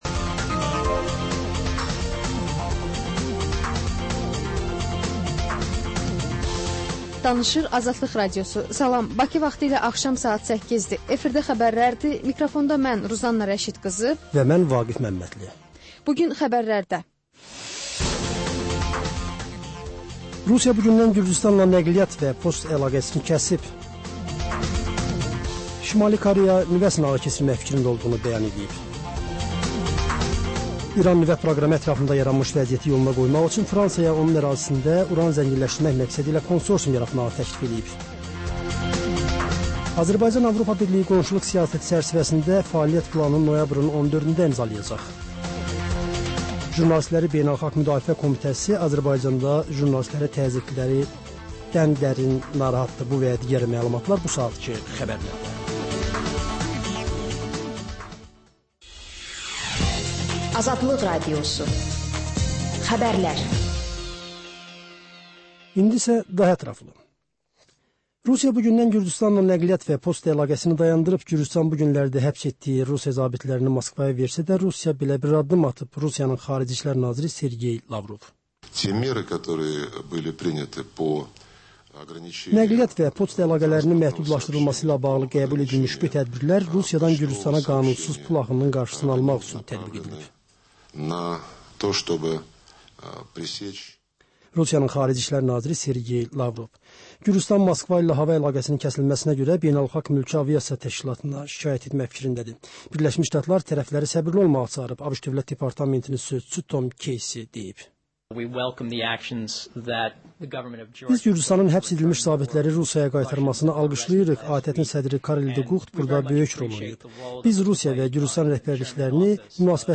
Xəbərlər, reportajlar, müsahibələr. Hadisələrin müzakirəsi, təhlillər, xüsusi reportajlar. Və sonda: Şəffaflıq: Korrupsiya barədə xüsusi veriliş.